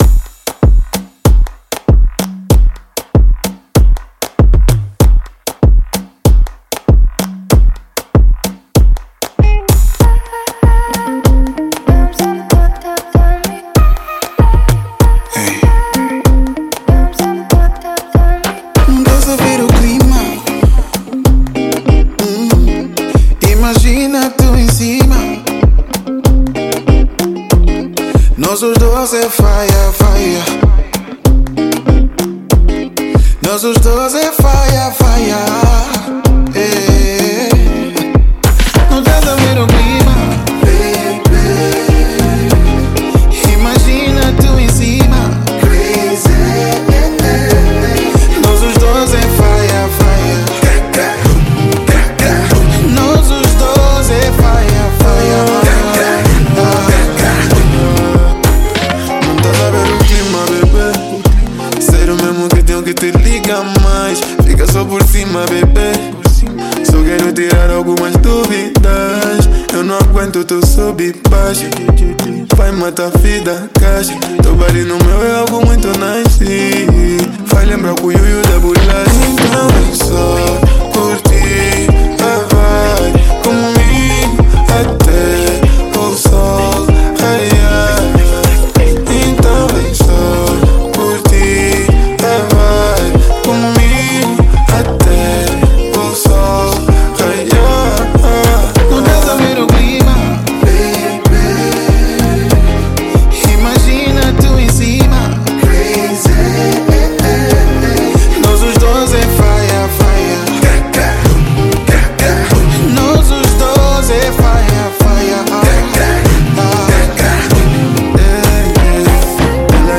Gênero: Rnb